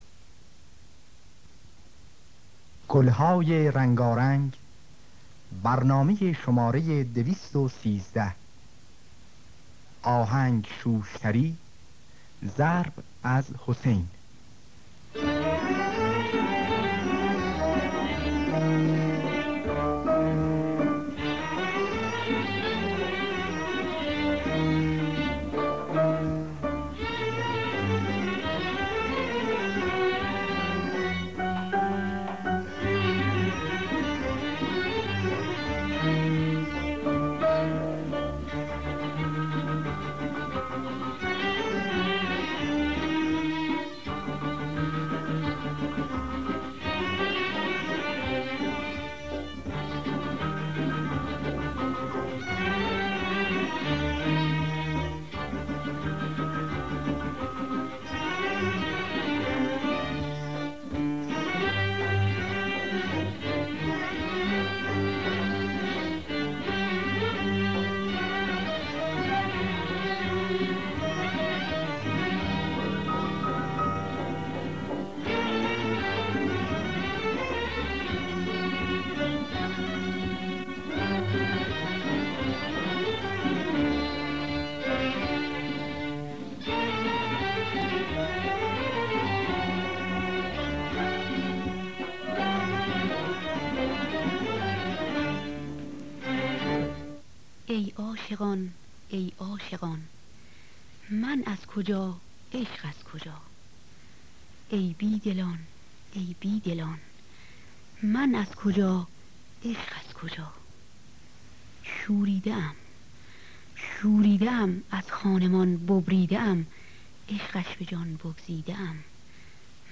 در دستگاه همایون